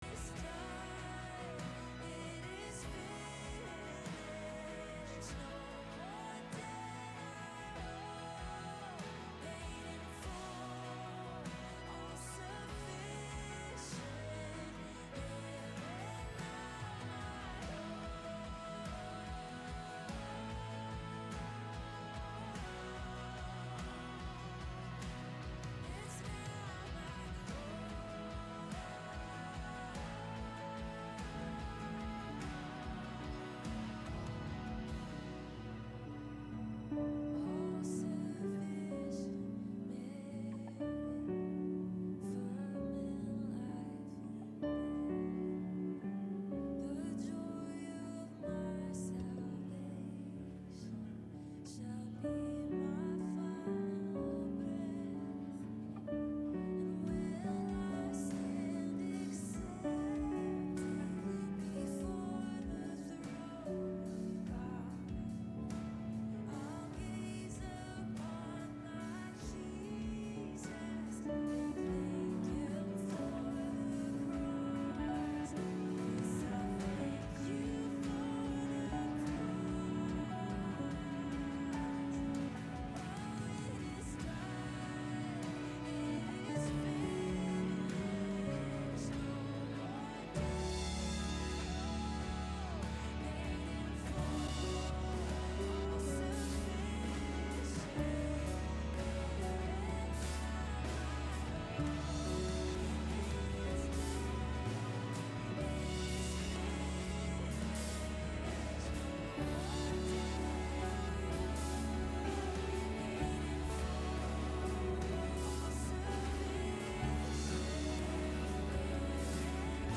Bulletin and Sermon Notes 4-27-25